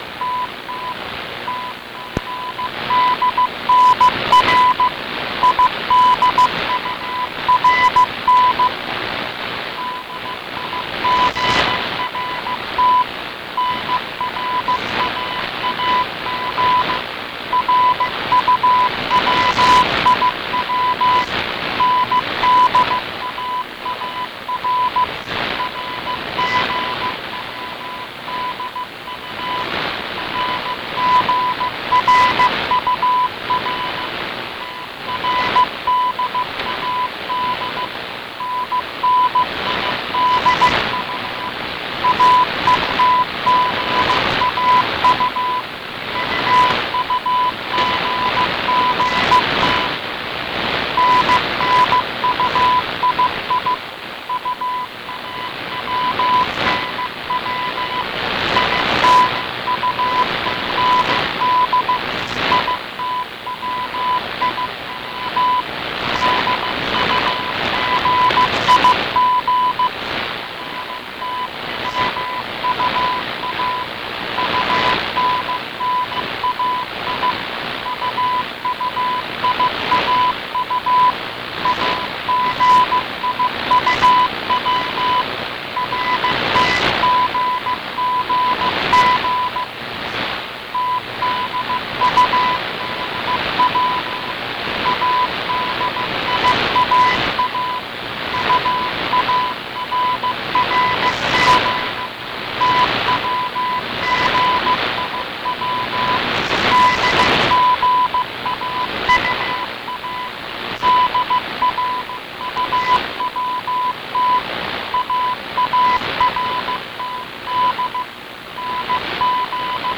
Identify this CW on AM...
It is perfect keying on 5.8MHz but i find it odd not being able to listen on another carrier. i tried to use Notch filter which didnt help because i guess its junky on AM. but listen in and let me know what you think it says.